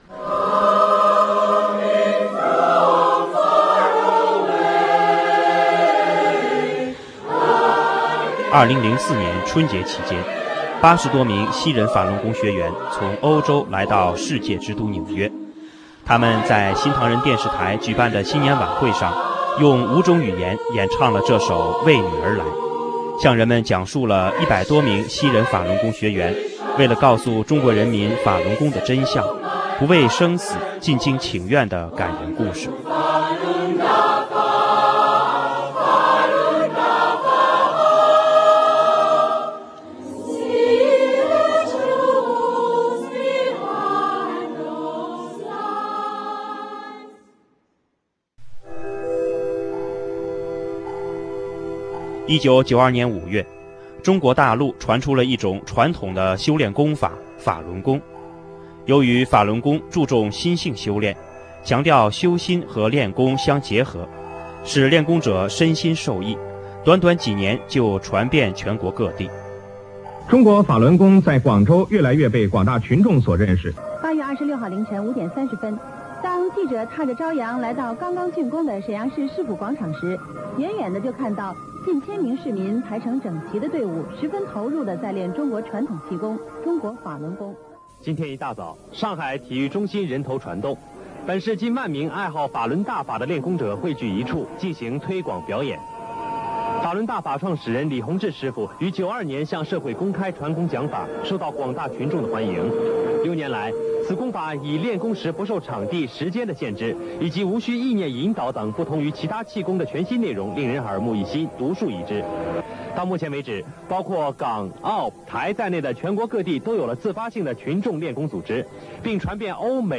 2004年春节期间，80多名西人法轮功学员从欧洲来到世界之都纽约，他们在新唐人电视台举办的新年晚会上用5种语言演唱了这首“为你而来”，向人们讲述了100多名西人法轮功学员为了告诉中国人民法轮功的真相，不畏生死进京请愿的感人故事……